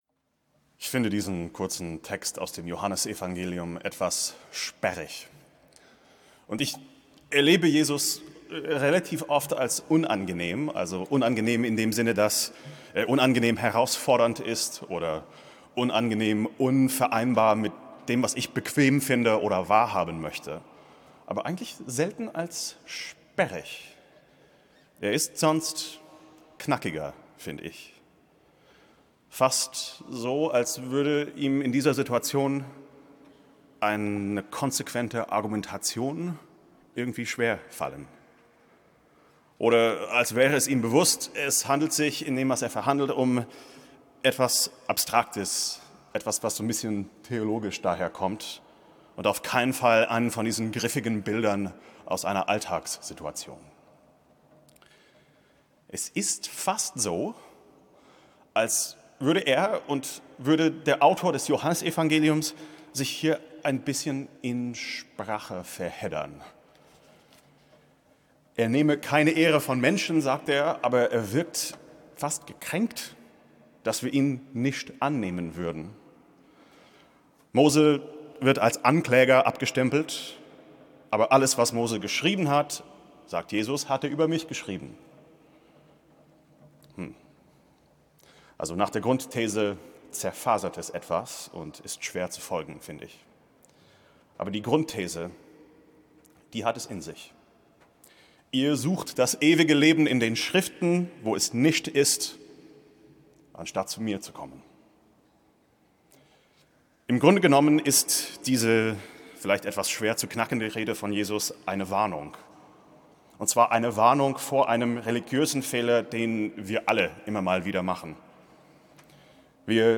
Predigten 2023